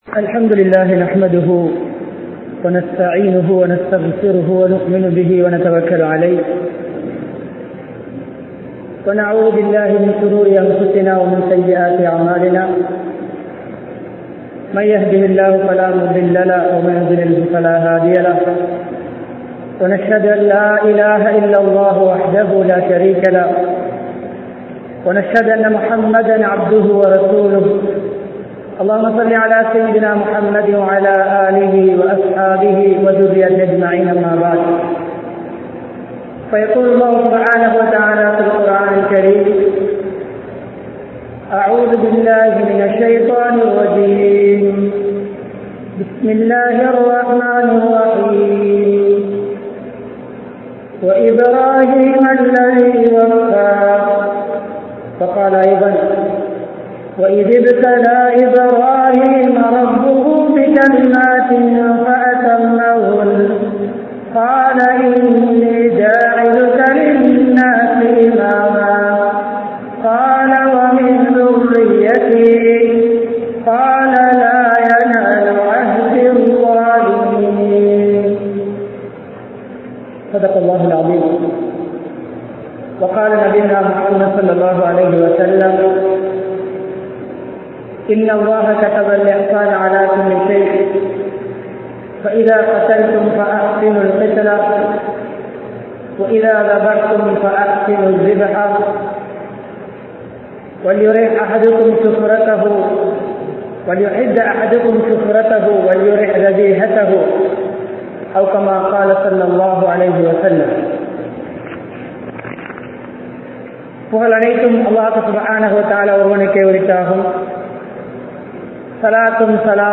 Ibrahim(Alai)Avarhalin Alahiya Mun Maathirihal (இப்றாஹீம்(அலை)அவர்களின் அழகிய முன்மாதிரிகள்) | Audio Bayans | All Ceylon Muslim Youth Community | Addalaichenai
Musalpitiya Muhiyuddeen Jumua Masjith